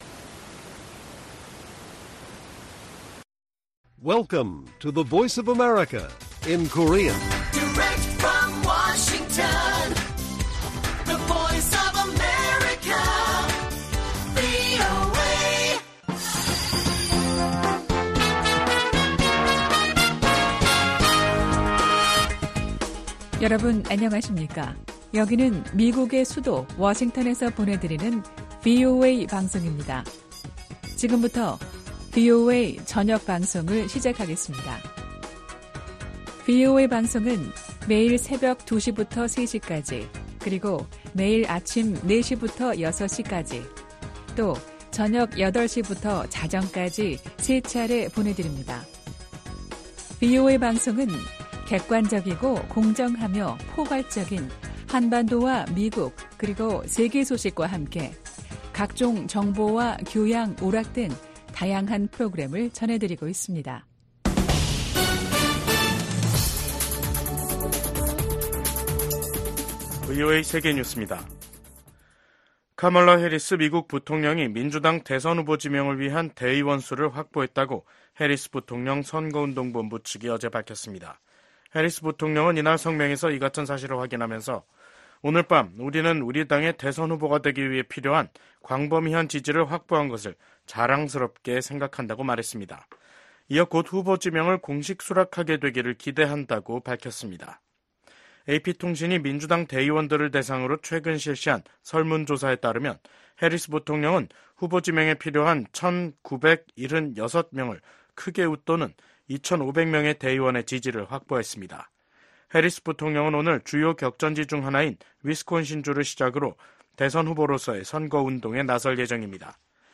VOA 한국어 간판 뉴스 프로그램 '뉴스 투데이', 2024년 7월 23일 1부 방송입니다. 미 국무부 고위 관리는 오는 27일 열리는 아세안지역안보포럼을 계기로 한 미북 대화는 없을 것이라고 밝혔습니다. 북한과 러시아의 군사 협력이 노골화하는 가운데 오는 28일 도꾜에서 미한일 국방장관 회담이 열립니다. 2026년 핵확산금지조약(NPT) 평가회의를 위한 준비위원회가 개막한 가운데 각국이 북한의 완전한 비핵화를 촉구했습니다.